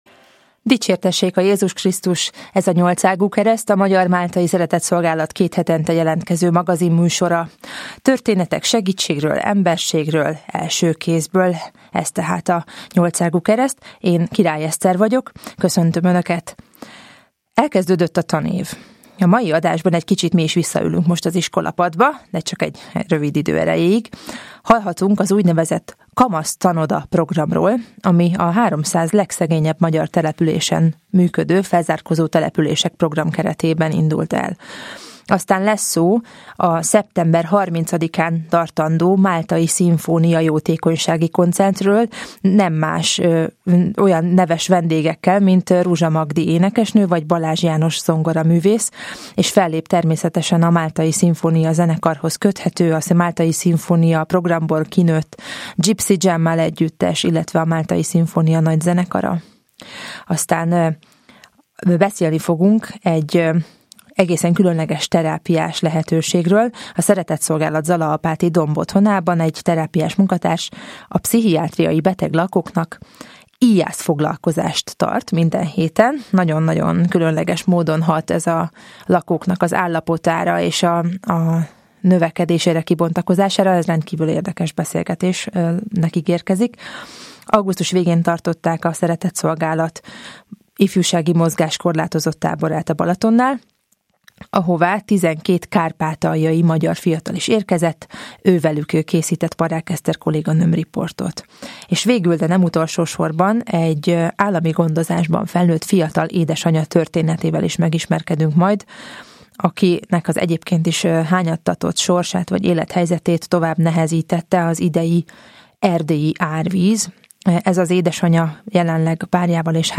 Hallgassák meg a Kamasztanodákról szóló riportot a Mária Rádió Nyolcágú Kereszt című műsorában.